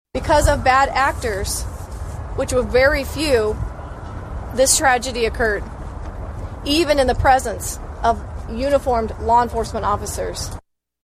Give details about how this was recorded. Some details were released during a news conference Thursday morning.